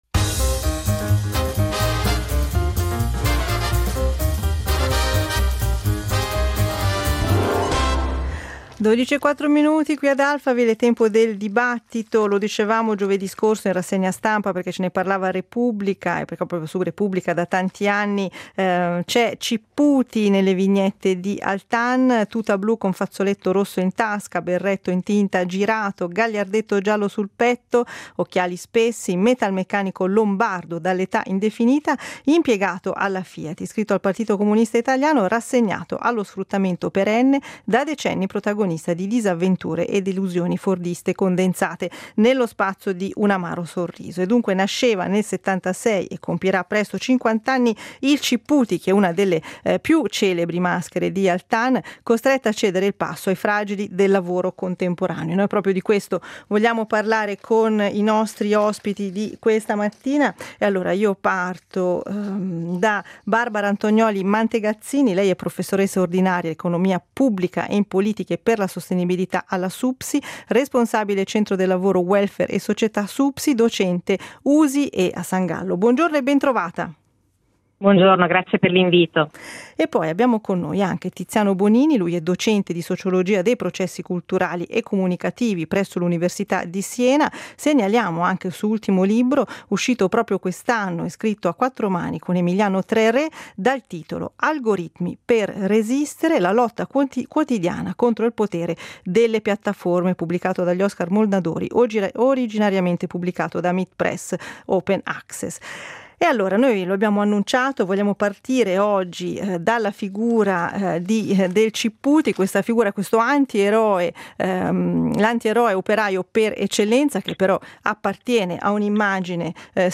Nella nostra mezz’ora di dibattito abbiamo cercato di capire come è composto il nuovo mondo del lavoro, composto da una varietà di profili. Ci siamo confrontati con due ospiti: